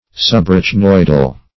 Search Result for " subarachnoidal" : The Collaborative International Dictionary of English v.0.48: Subarachnoid \Sub`a*rach"noid\, Subarachnoidal \Sub*ar`ach*noid"al\, a. (Anat.) Situated under the arachnoid membrane.
subarachnoidal.mp3